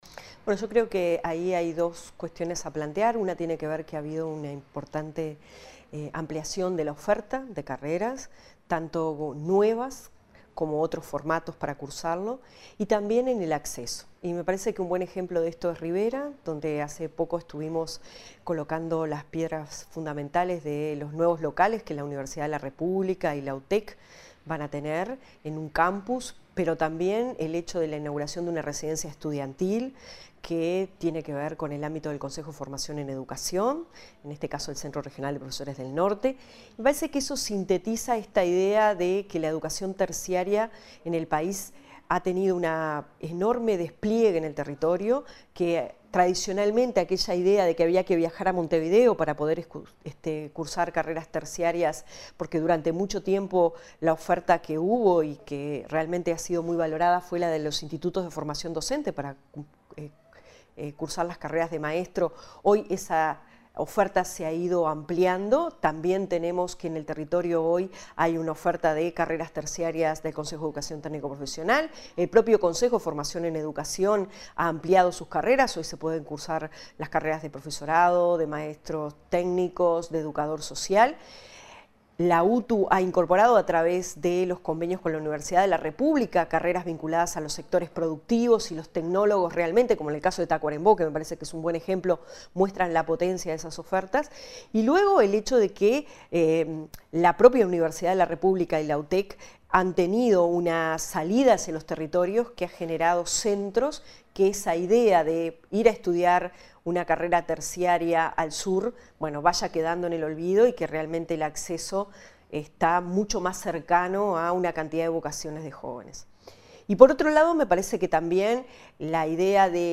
“La educación terciaria en el país ha tenido un enorme despliegue en el territorio en los últimos años”, aseguró la directora nacional de Educación, Rosita Ángelo. La jerarca se refirió a la ampliación de la oferta educativa, como las carreras de UTU vinculadas a sectores productivos en Tacuarembó y el Polo de educación terciaria de Rivera, a la que se suman las de Udelar y UTEC y los bachilleratos artístico y de deporte.